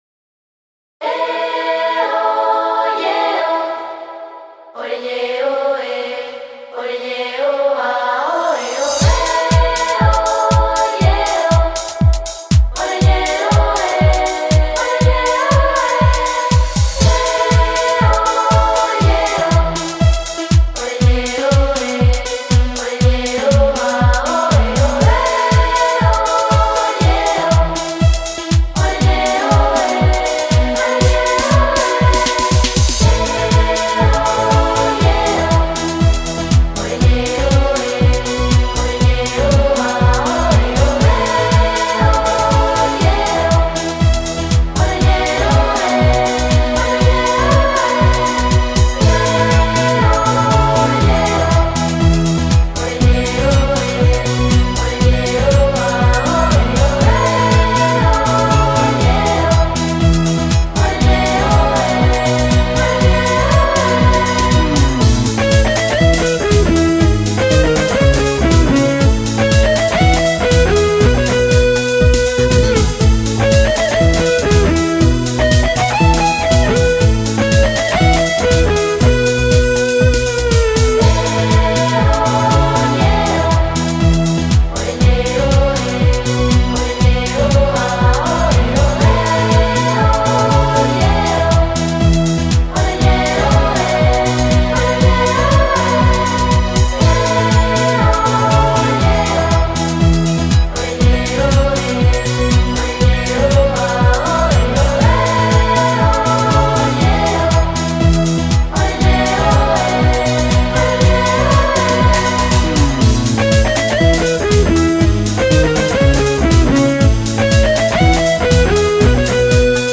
Sintonía
interpretada por un coro de jóvenes voces